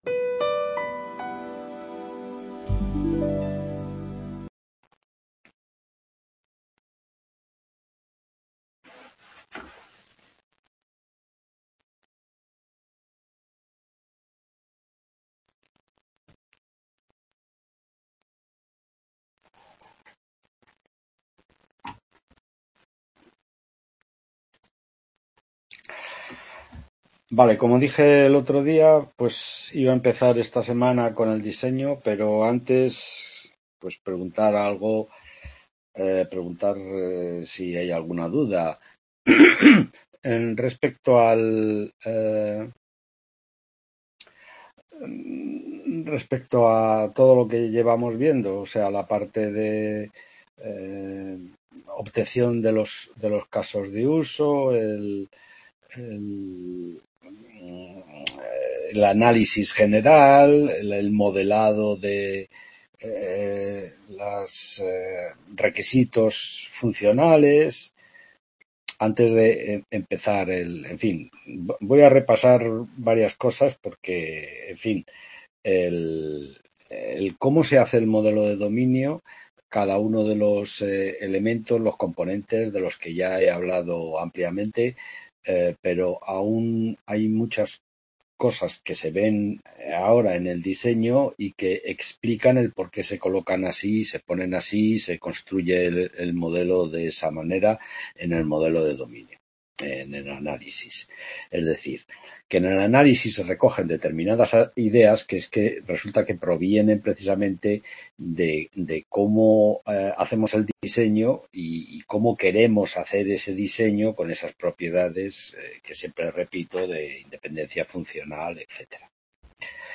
2024-12-16 9ª Tutoría Diseño de Software.